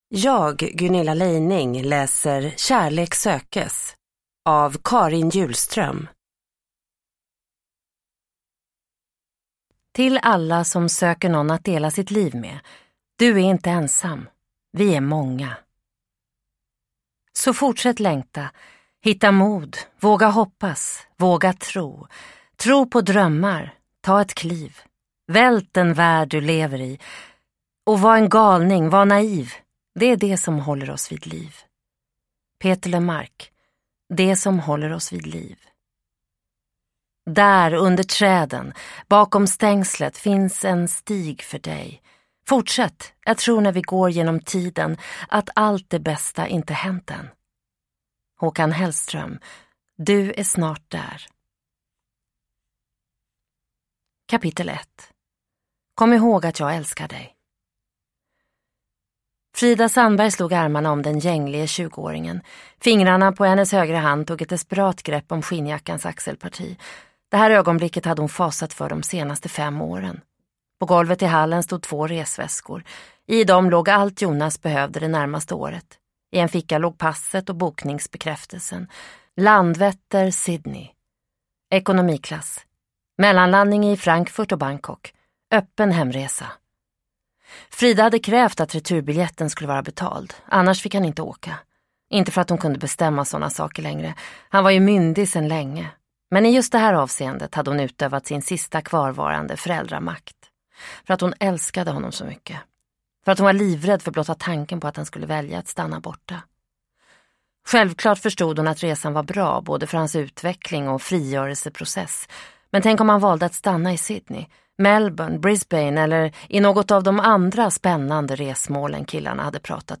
Kärlek sökes – Ljudbok – Laddas ner